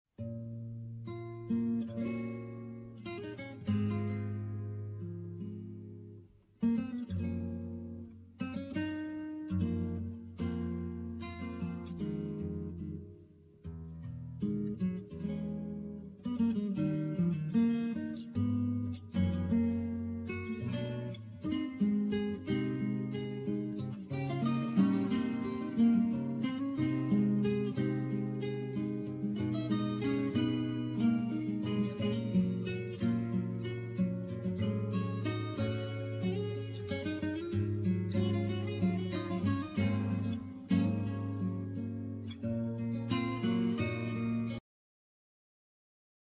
Alto sax,synthesizer
Piano,Keyboards
Guitar,Sitar
Bass
Percussion
Tenor sax
Trumpet
Drums